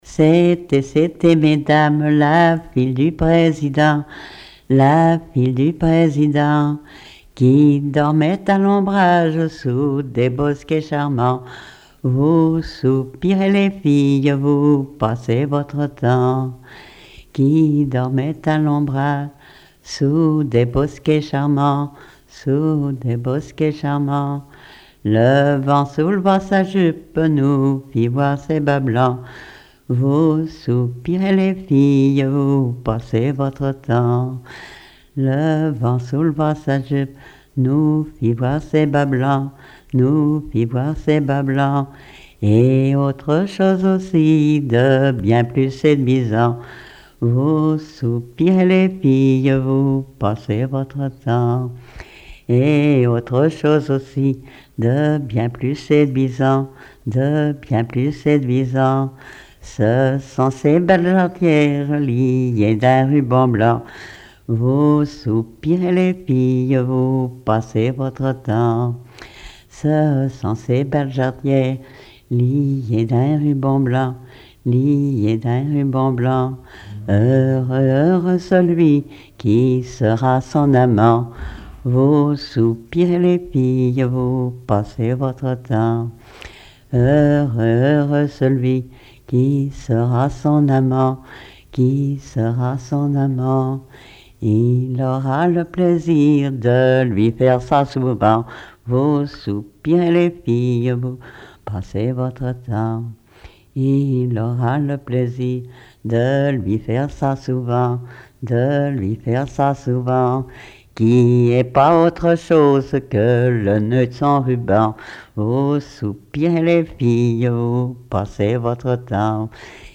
Genre laisse
témoignages et chansons traditionnelles
Pièce musicale inédite